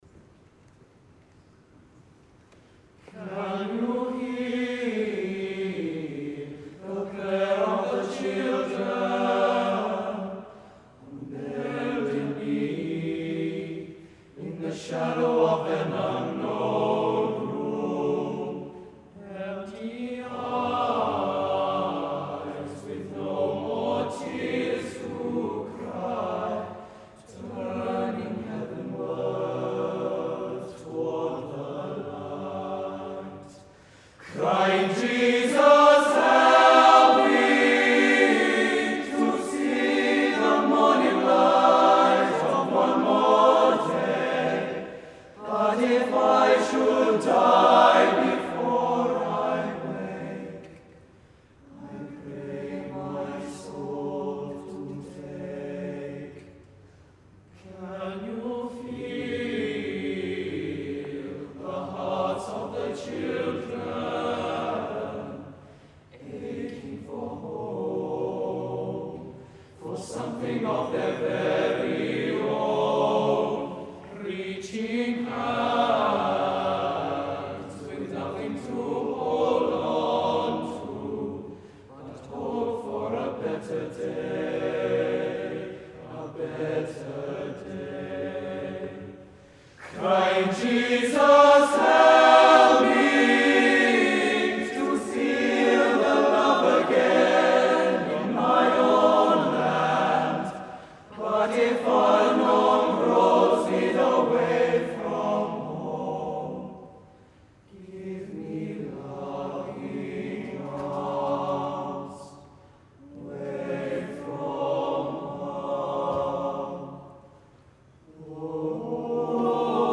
Recordings from The Big Sing National Final.
Otago Boys' High School Choir 2011 Otago Boys' High School Prayer of the Children Loading the player ...